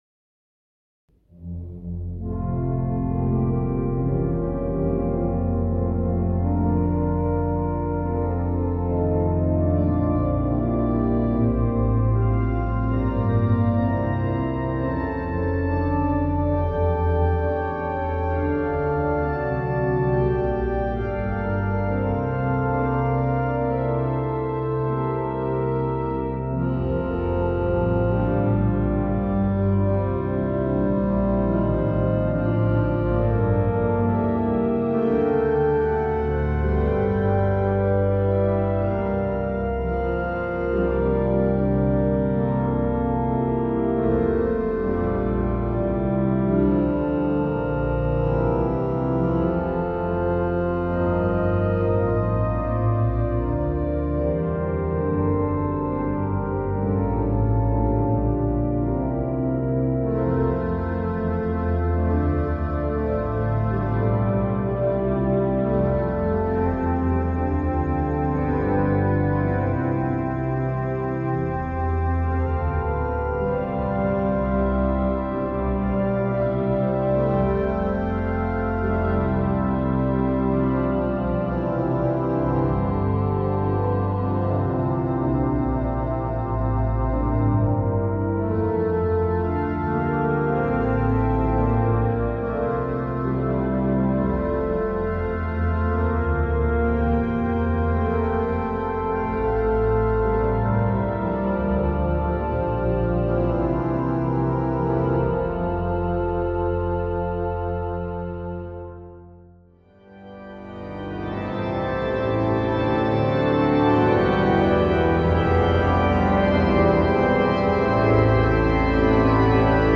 Een korte opname in een lege kerk. Stijl Hollands-Romantisch.
Goes-Magdalena-kerk-Ecclesia-2024-telefoonopname-website.mp3